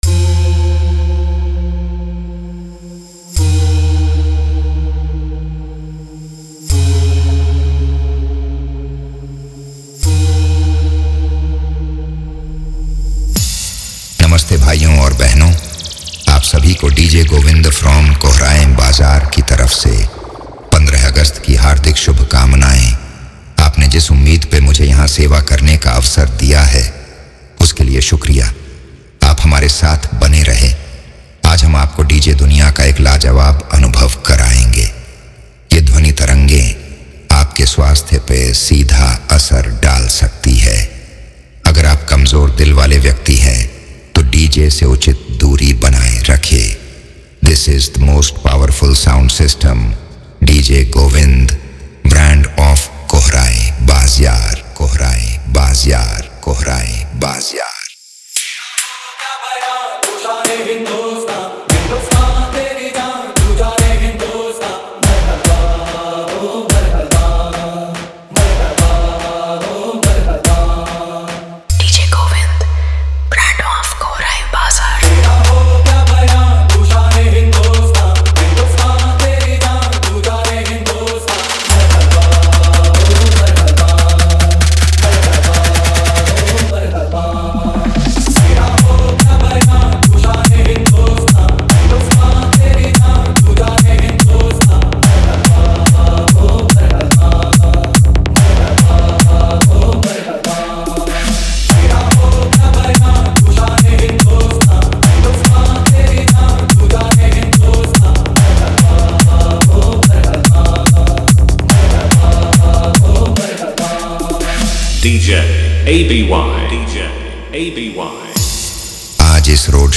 Independence Day Dj Remix
Desh Bhakti Dj Song
Dj Beat Song